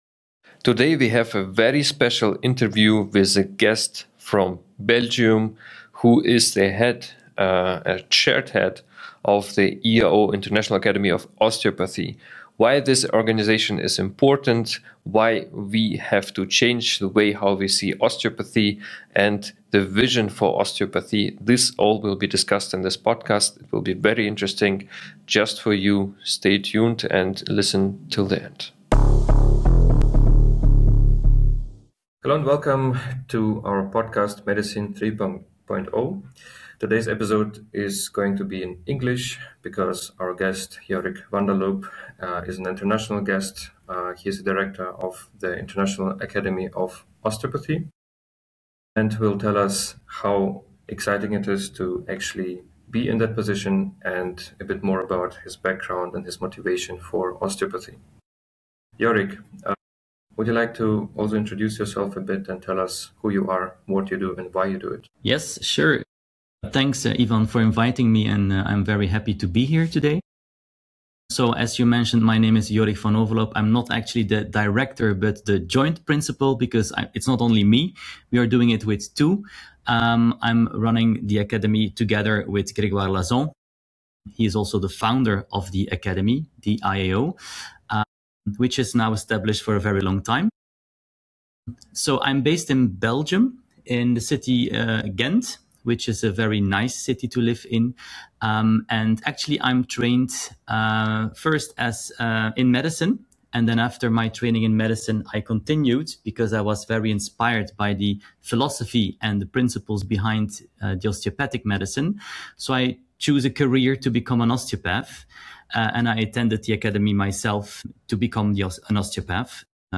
Im Fokus stehen die Rolle von Anatomie, Wissenschaft und klinischem Denken, die Ausbildung zukünftiger Osteopathinnen und Osteopathen sowie die Frage, wie sich Osteopathie zwischen Tradition, Evidenz und moderner Gesundheitsversorgung weiterentwickeln kann. Das Gespräch beleuchtet sowohl historische Wurzeln als auch aktuelle Herausforderungen wie Forschung, Regulierung, Qualitätssicherung und die zunehmende Bedeutung interprofessioneller Zusammenarbeit.